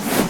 player_broom_attack.ogg